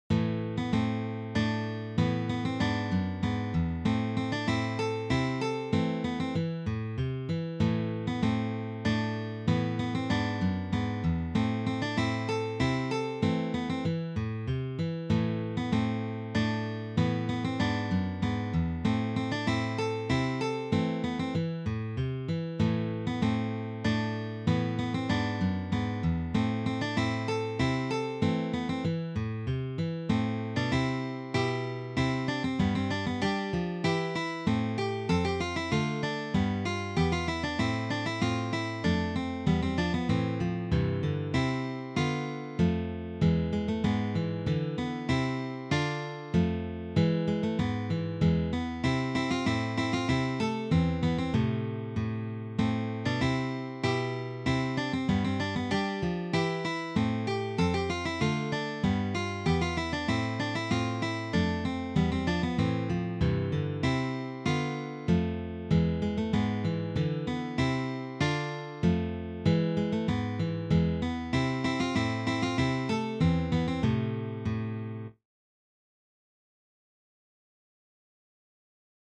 arranged for three guitars
This Baroque selection is arranged for guitar trio.